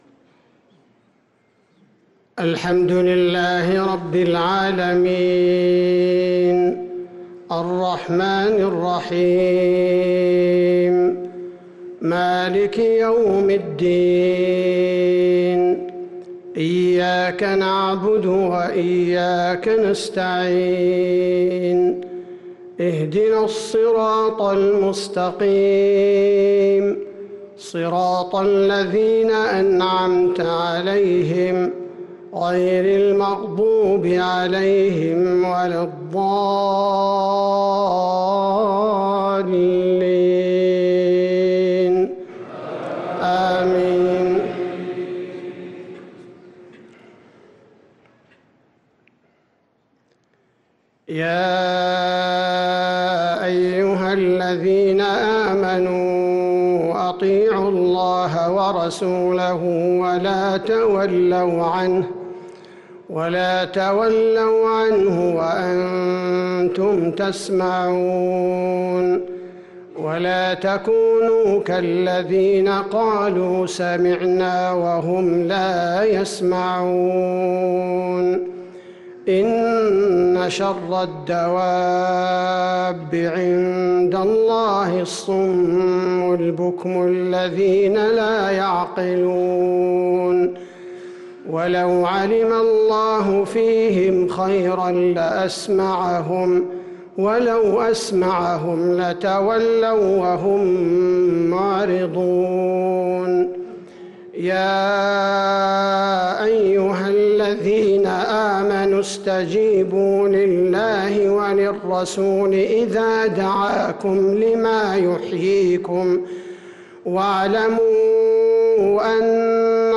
صلاة العشاء للقارئ عبدالباري الثبيتي 7 ربيع الأول 1444 هـ
تِلَاوَات الْحَرَمَيْن .